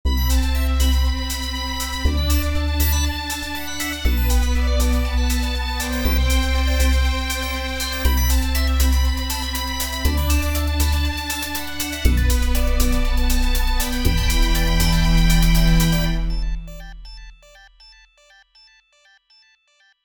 「HOSHIYOMIDO」 表にそって音階に置き換えると、 「シラミシシレラファシレラ」 これを簡単にアレンジすると、 短いリフレインが出来上がりました。 ほしよみ堂.m4a ※注）クリックすると音が流れます ちょっと切ない系にしてみました。